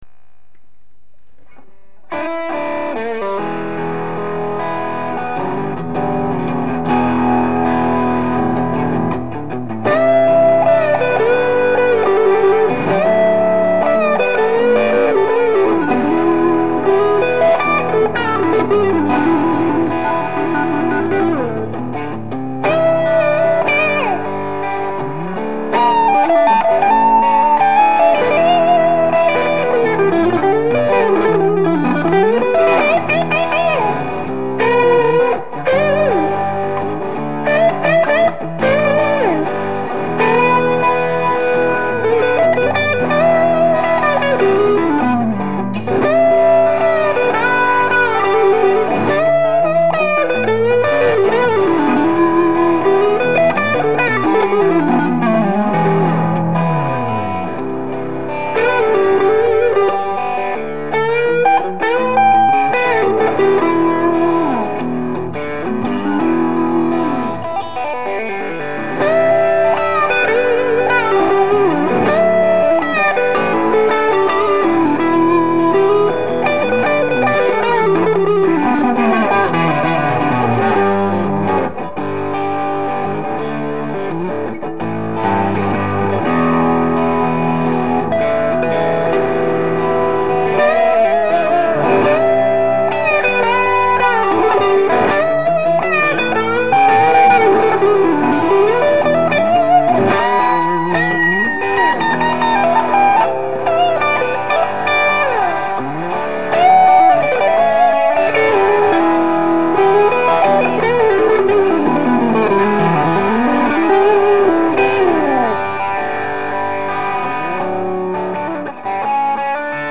mine de rien j'ai entendu des samples d'un mec qui a acheté une telecaster chez Ishibashi je vous file les liens vers les samples ca arrache tout